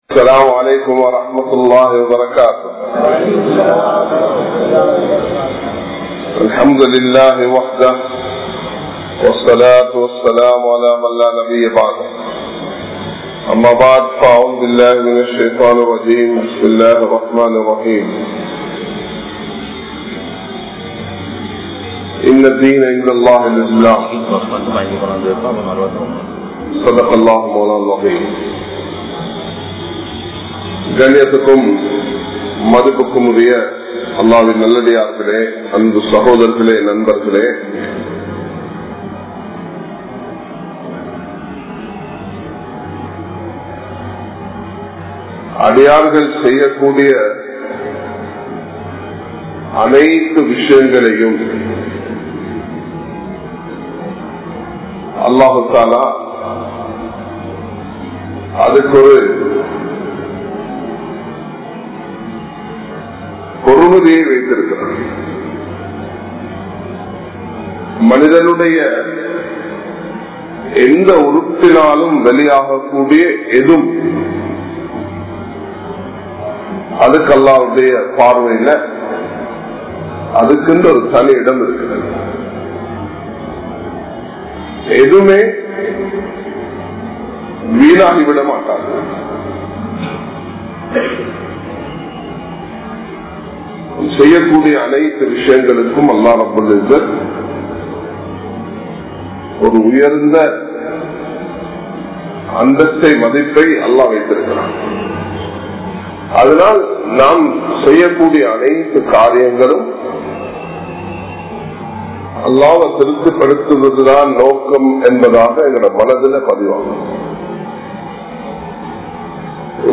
Ramalaanai Amalhalaal Alangarippoam (ரமழானை அமல்களால் அலங்கரிப்போம்) | Audio Bayans | All Ceylon Muslim Youth Community | Addalaichenai